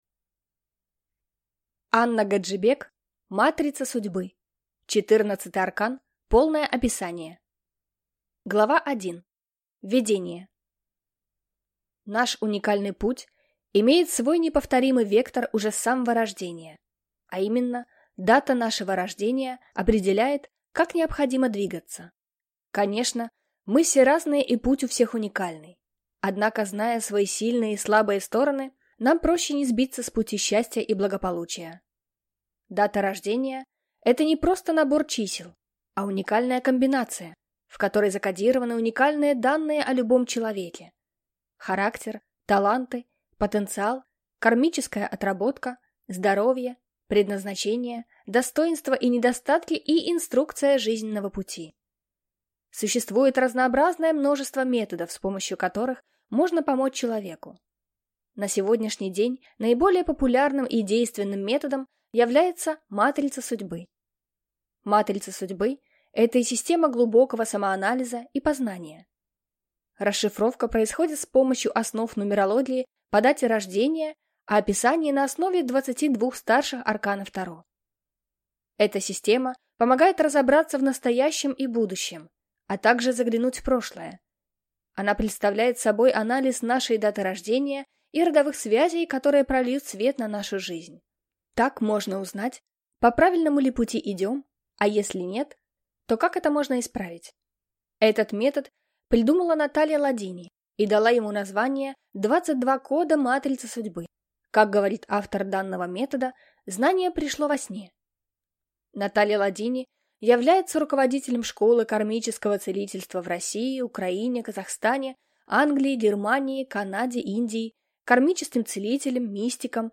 Аудиокнига Матрица Судьбы. Четырнадцатый Аркан. Полное описание | Библиотека аудиокниг